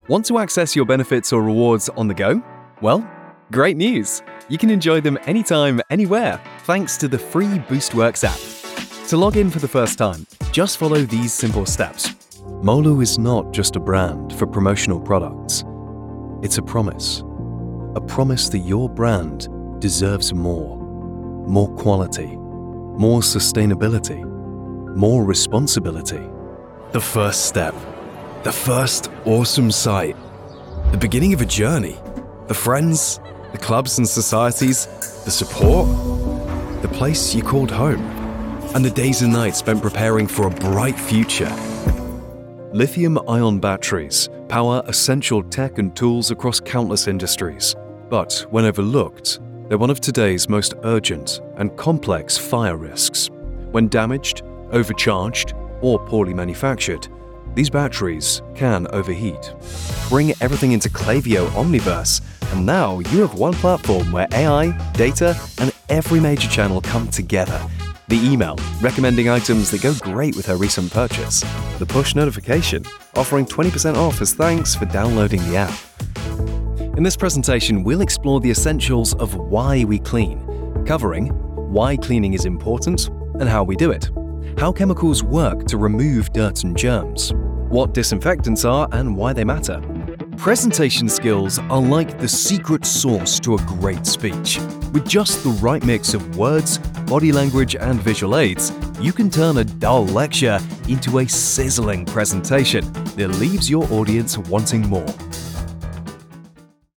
Comercial, Natural, Amable, Cálida, Versátil
Corporativo